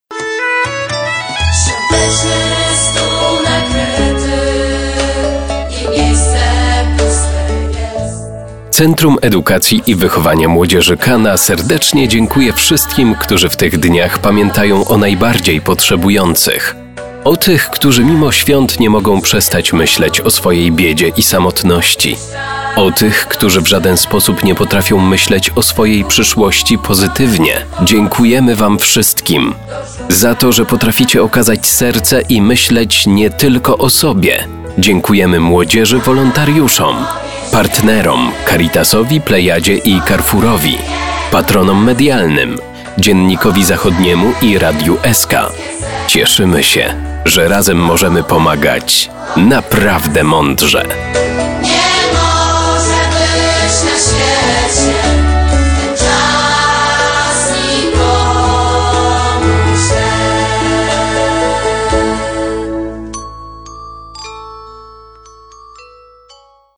Spot reklamowy z Centrum Handlowego